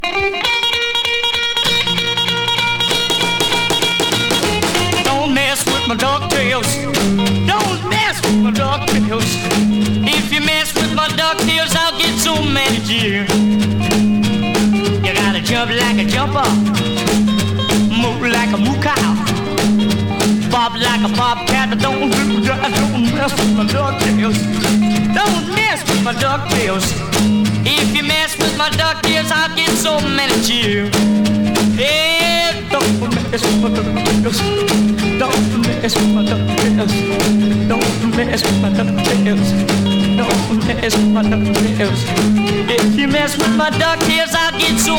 Rock & Roll, Rockabilly　Germany　12inchレコード　33rpm　Mono